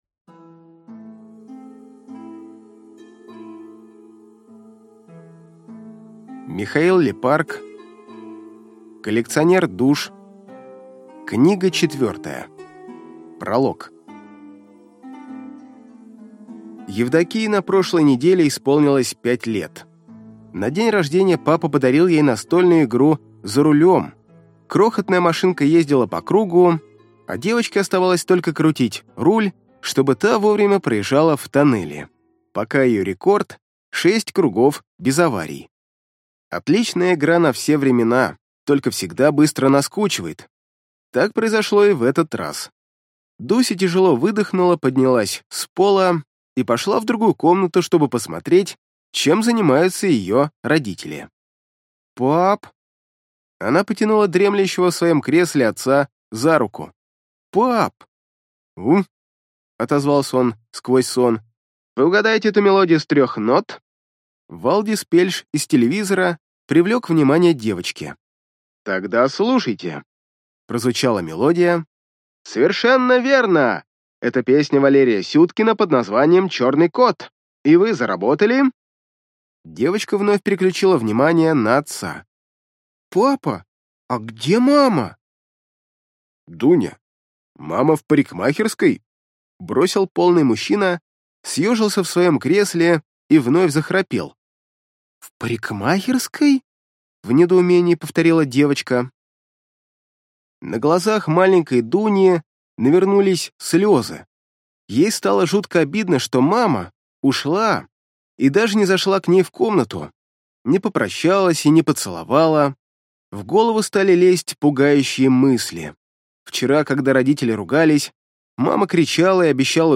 Аудиокнига Коллекционер душ. Книга 4 | Библиотека аудиокниг